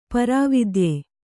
♪ parā vidye